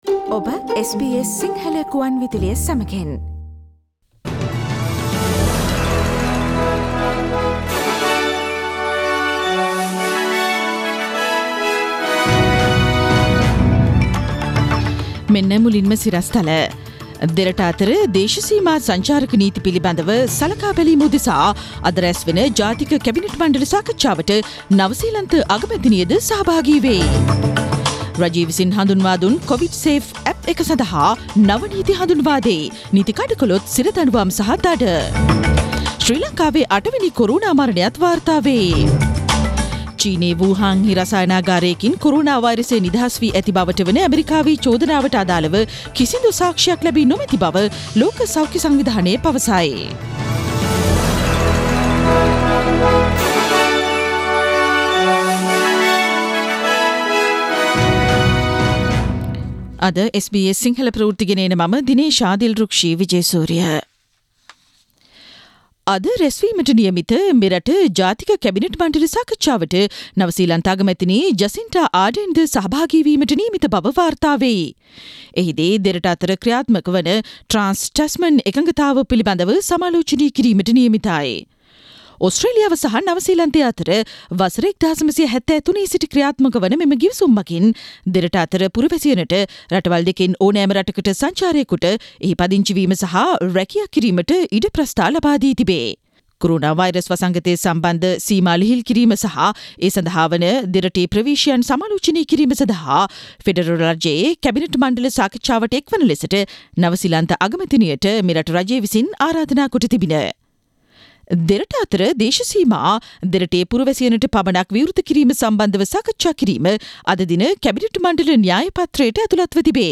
Today’s news bulletin of SBS Sinhala radio – Tuesday 5 May 2020